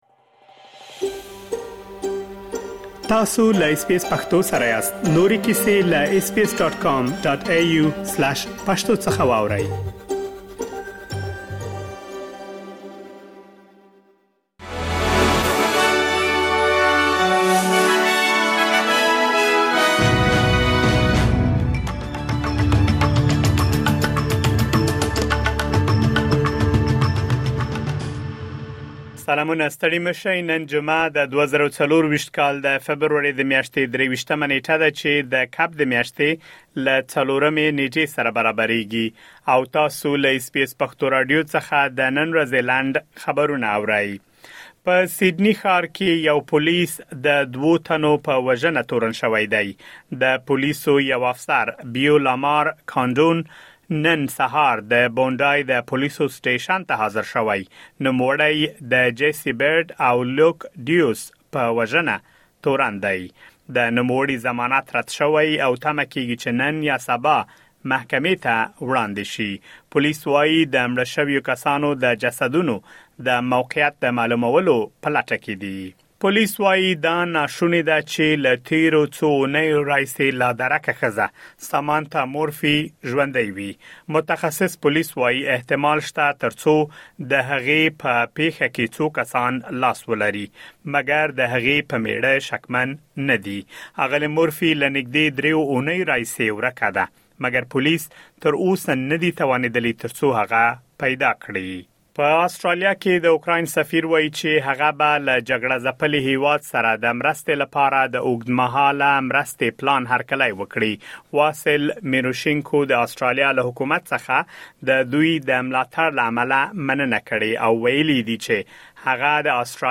د اس بي اس پښتو راډیو د نن ورځې لنډ خبرونه |۲۳ فبروري ۲۰۲۴